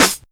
DreSnr11.wav